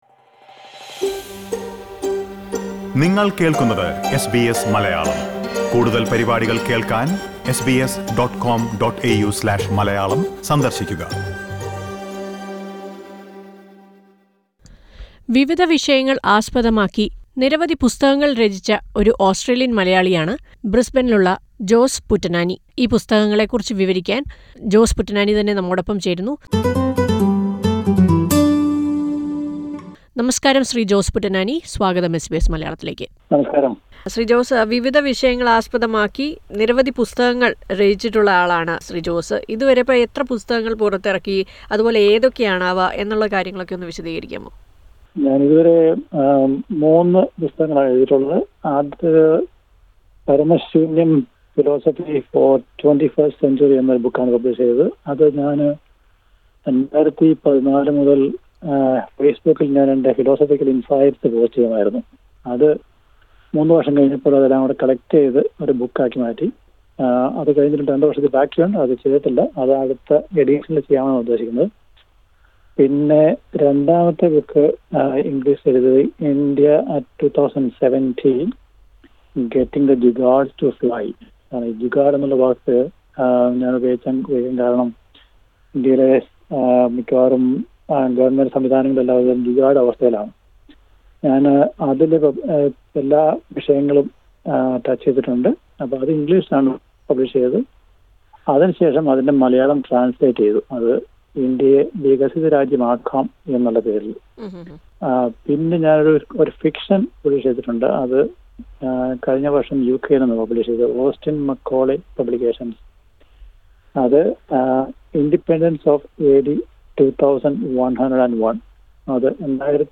ഈ പുസ്തകങ്ങളെക്കുറിച്ച് ഇദ്ദേഹം സംസാരിക്കുന്നത് കേൾക്കാം.